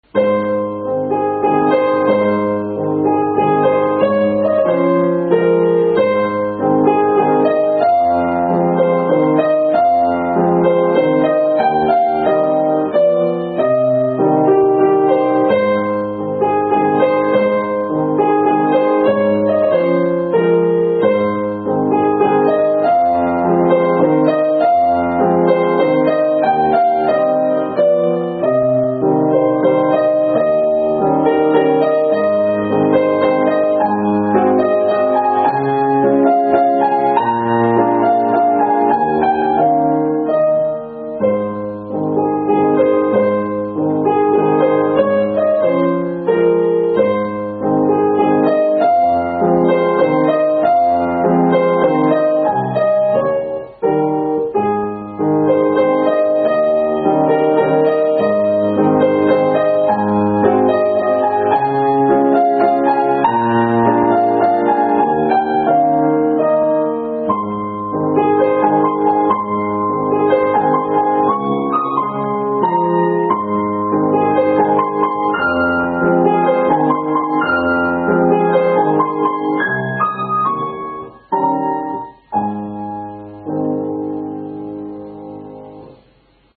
とてもロマンティックで優美な雰囲気の音楽なので、BGMなどでも使われています。
今回は、ピアノソロ用に編曲された曲を、更に弾きやすくアレンジして演奏しています。